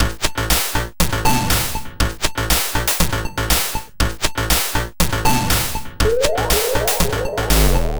/samples/CHIPSHOP_DELUXE/CHIPSHOP_LOOPS/120_BPM/
ChipShop_120_Combo_A_01.wav